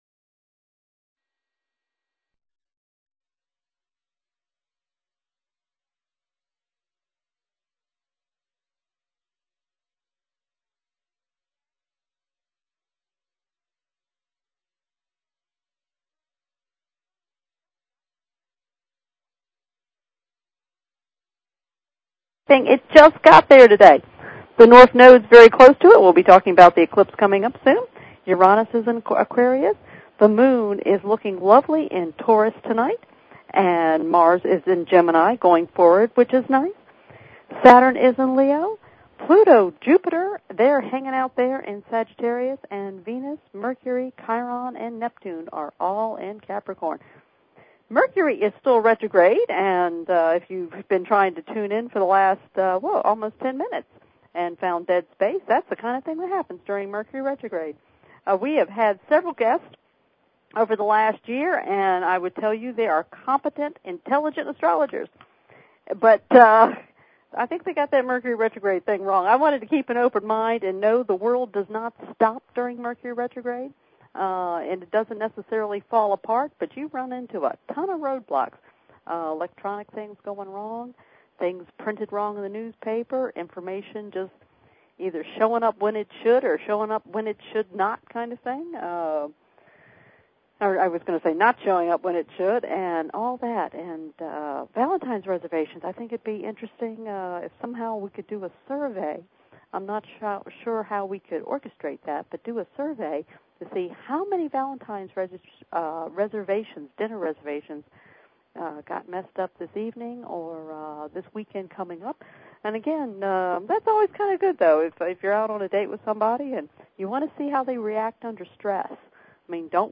Talk Show
You can e-mail and hear your answers on air.